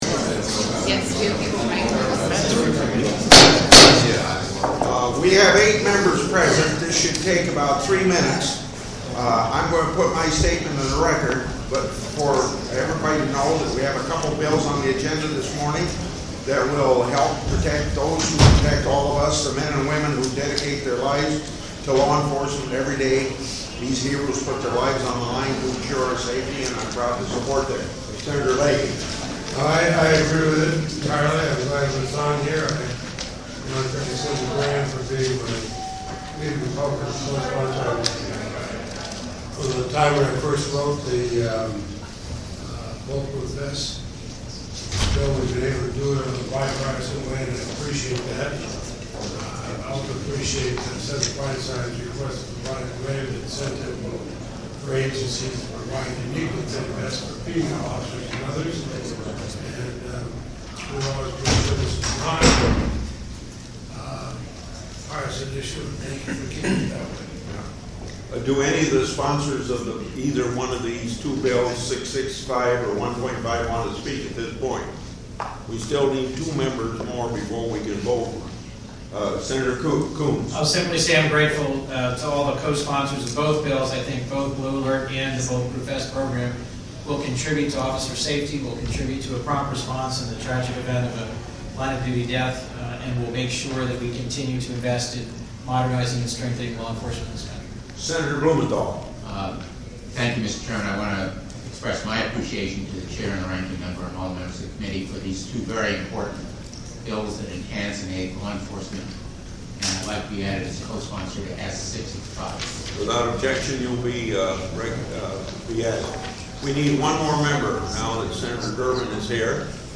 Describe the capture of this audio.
Location: Capitol S-211 Audio from the Executive Business Meeting can be found